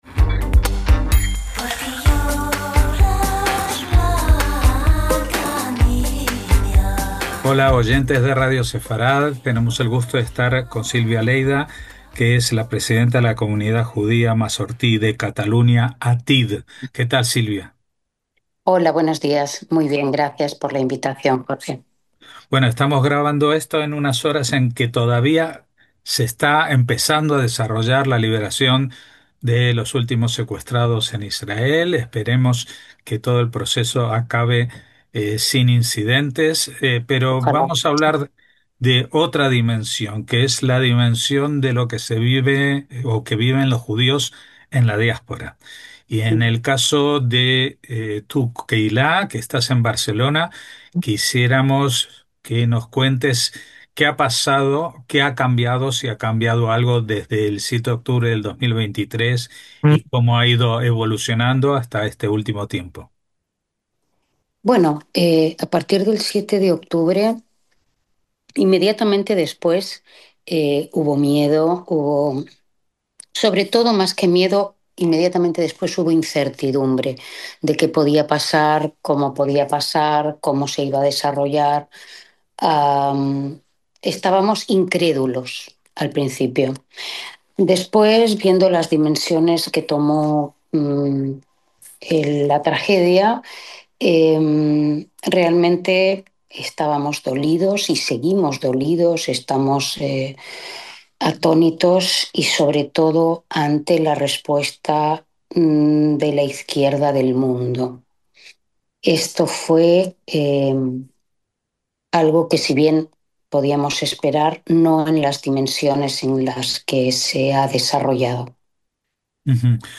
Grabamos esta estrevista a la misma hora que se desarrolla el intercambio de prisioneros entre Israel y Hamás que se supone es el primer paso para un cese del fuego definitivo. Pero esta guerra ha traspasado desde su inicio hace dos años todas las fronteras y ha sembrado de violencia y amenazas a todas las comunidades judías del mundo, incluidas las españolas.